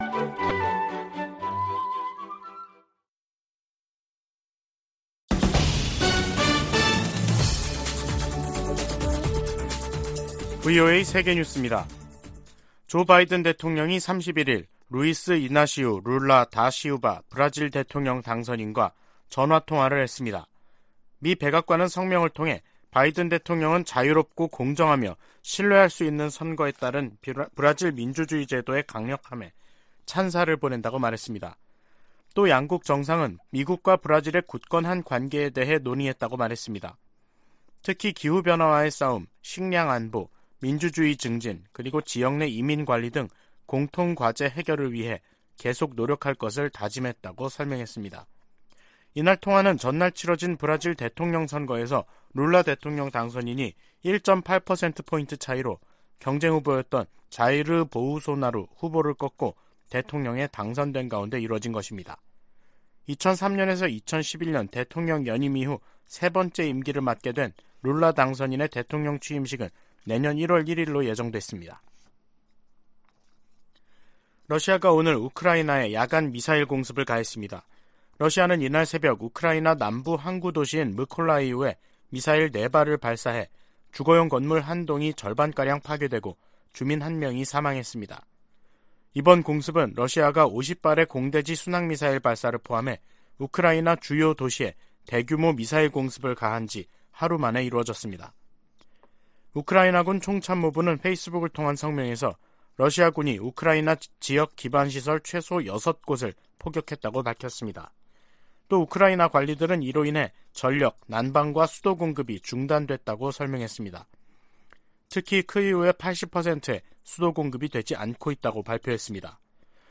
VOA 한국어 간판 뉴스 프로그램 '뉴스 투데이', 2022년 11월 1일 2부 방송입니다.